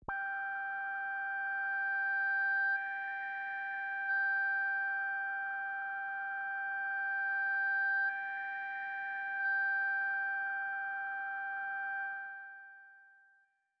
标签： FSharp5 MIDI音符-79 罗兰木星-4 合成器 单票据 多重采样
声道立体声